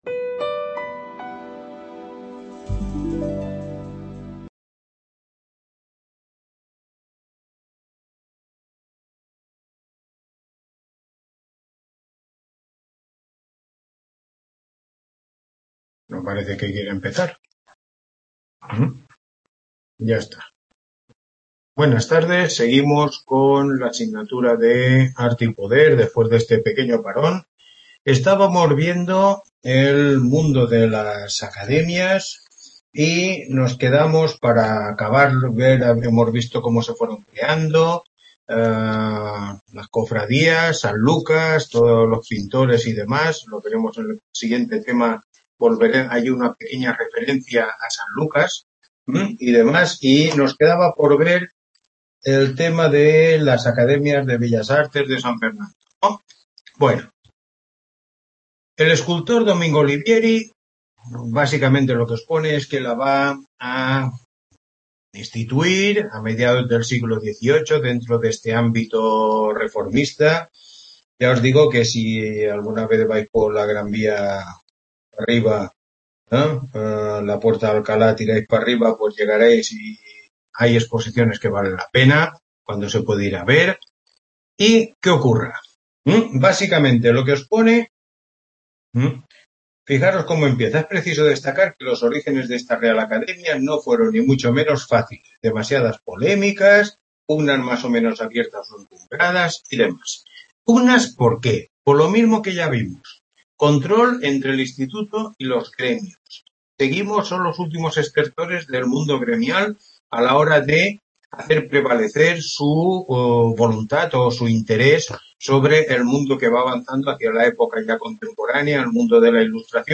Tutoría 9